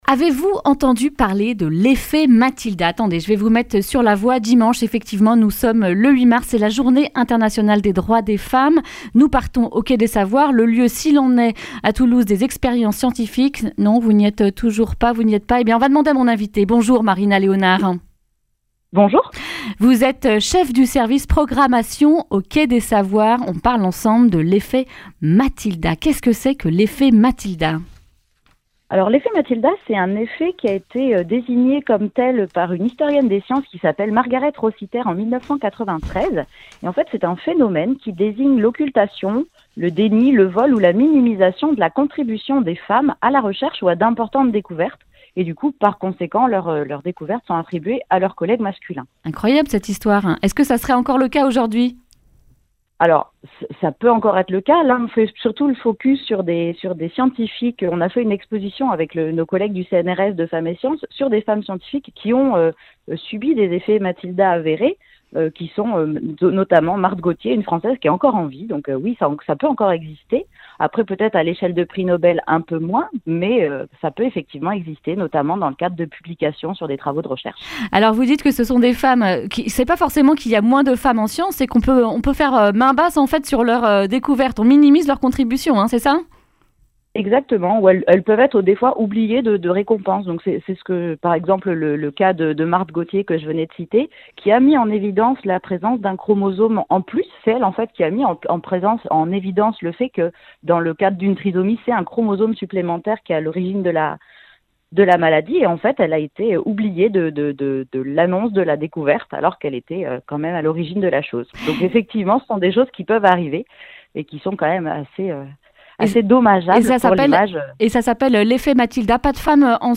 vendredi 6 mars 2020 Le grand entretien Durée 10 min